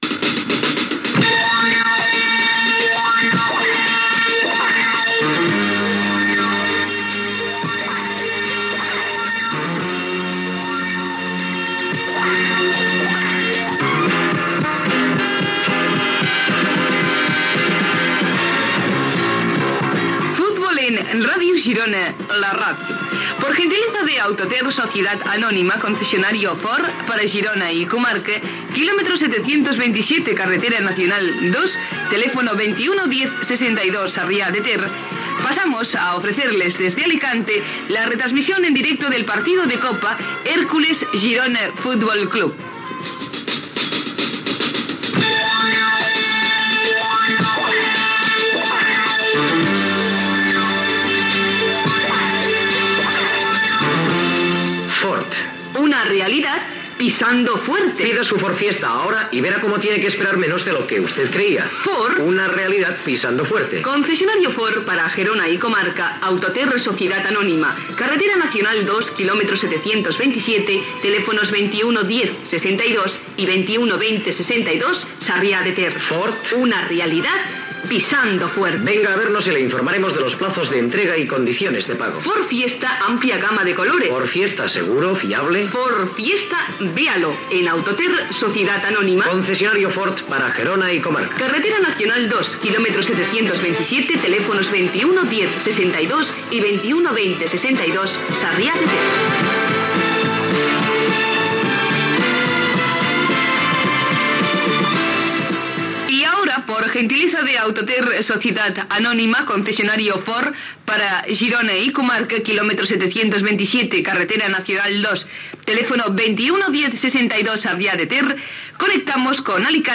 Careta del programa amb publicitat, inici de la retransmissió del partit de futbol masculí entre l'Hèrcules d'Alacant i el Girona FC corresponent a la tercera eliminatòria de la Copa del Rei. Presentació, aliniacions dels dos equips i narració de les primeres jugades
Esportiu